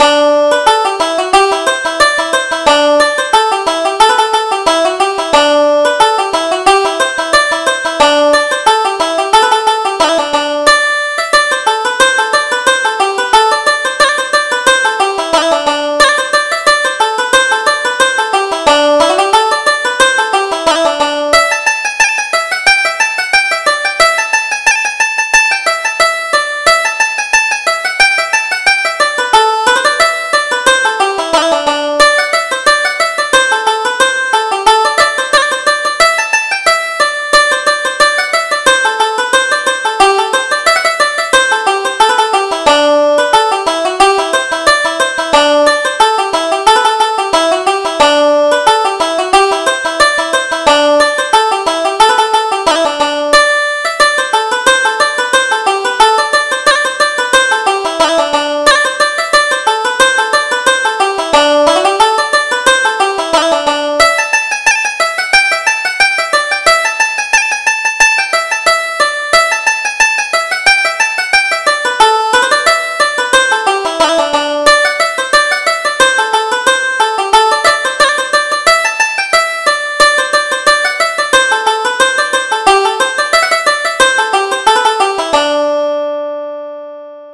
Reel: Jenny's Welcome to Charley